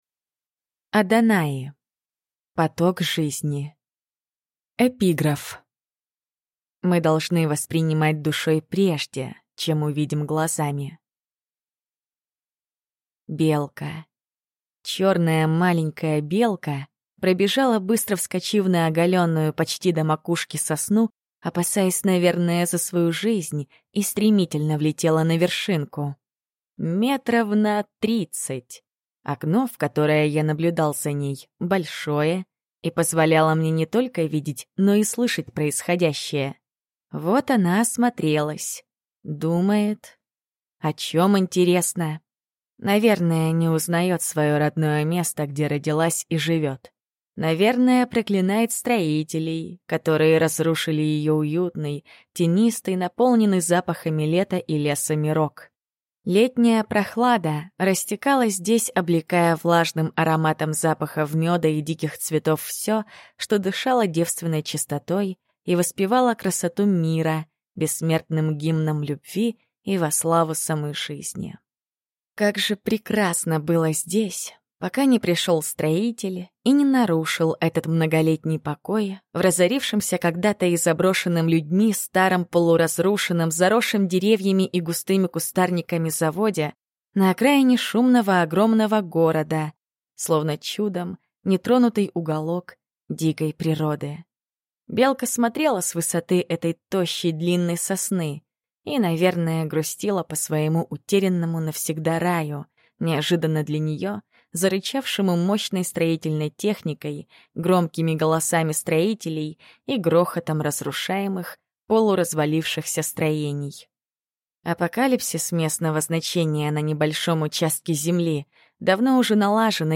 Аудиокнига Поток жизни. Горловская Мадонна. Ветхий деньми | Библиотека аудиокниг